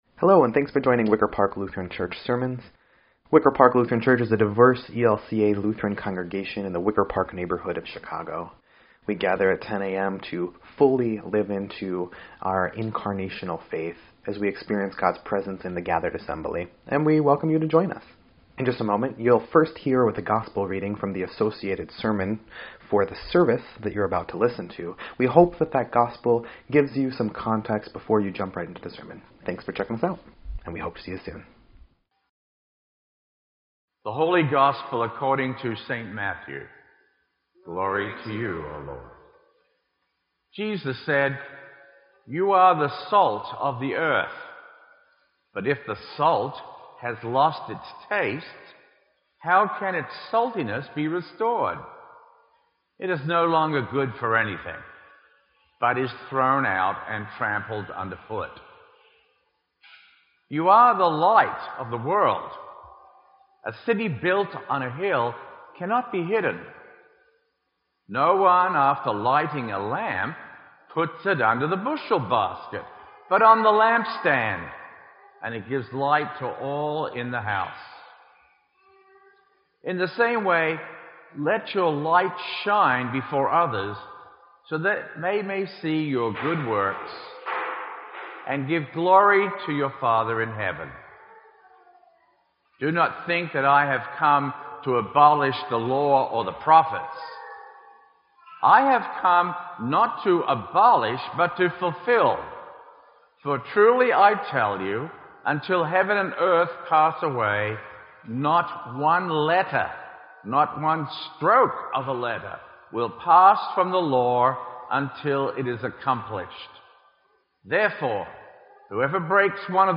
Sermon_2_5_17_EDIT.mp3